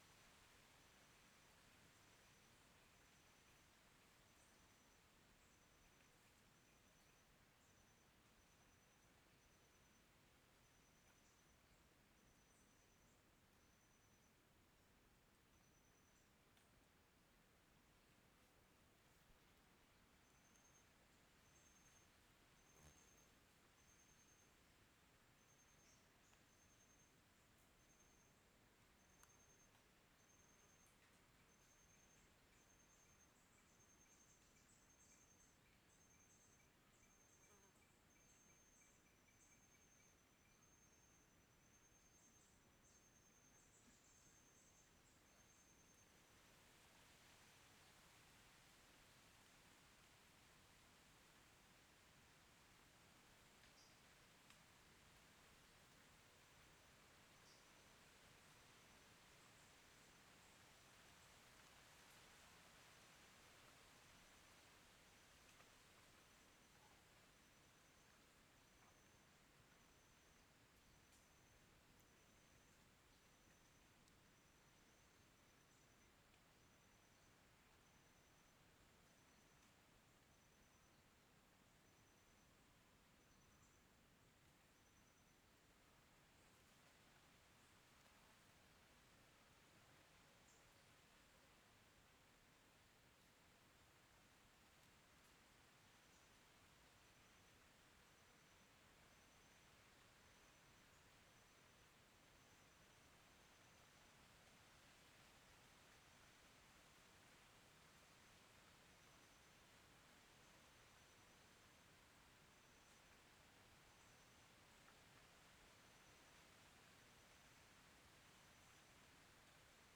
CSC-05-024-LE - Mata fechada com vento nas folhas de arvores, estalar de madeira e alguns insetos.wav